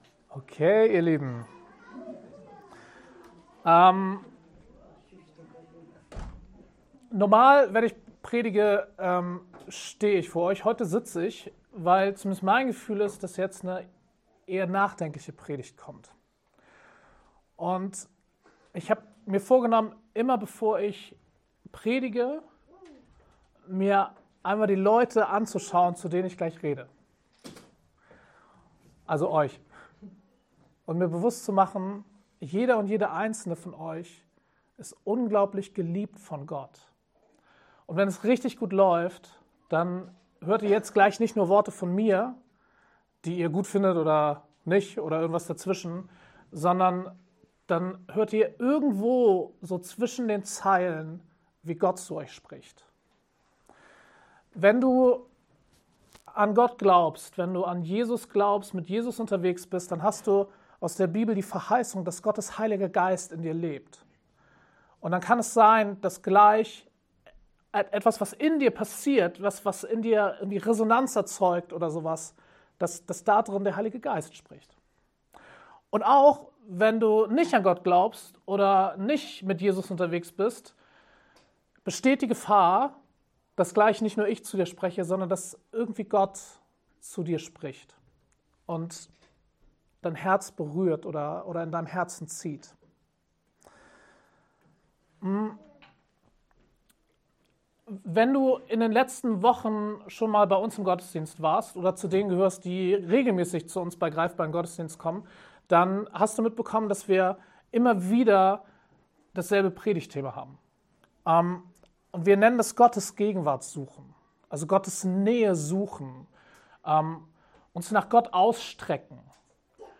Predigtpodcast
Diese Predigt ist eine Einladung, umzukehren und Buße zu tun – und Gottes Gegenwart zu suchen.